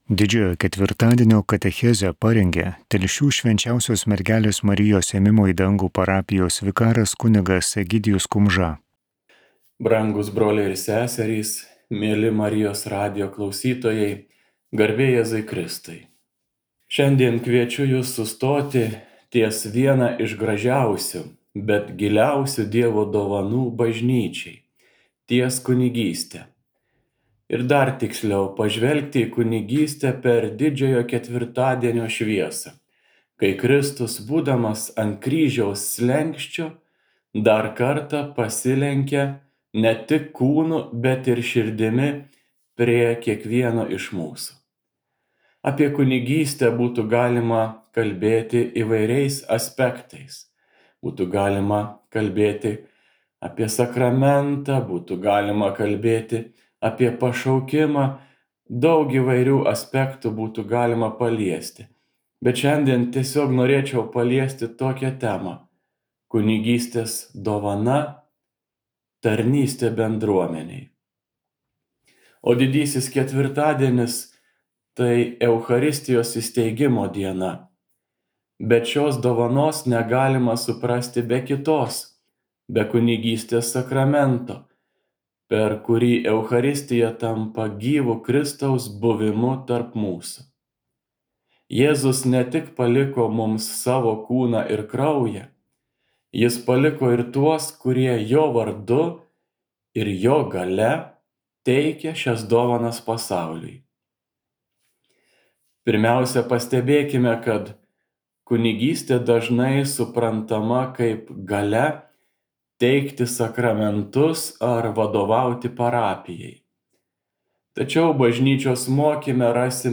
به Katechezė.